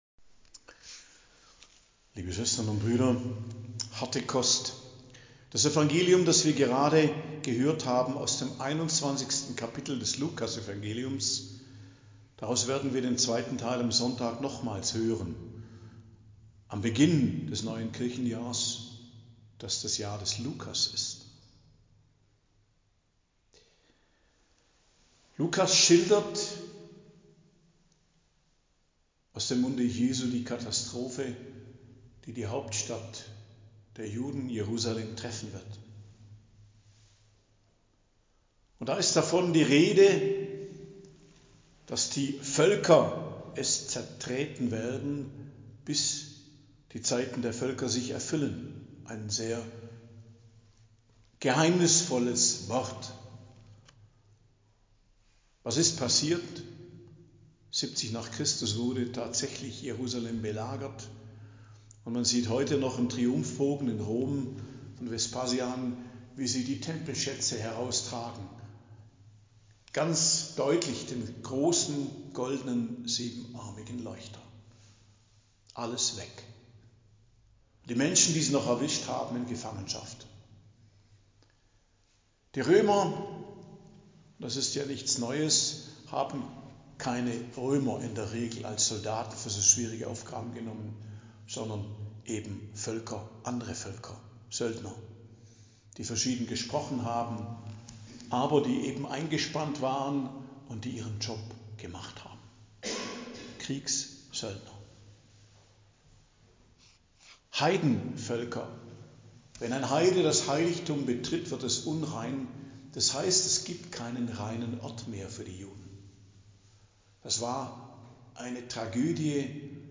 Predigt am Donnerstag der 34. Woche i.J. 28.11.2024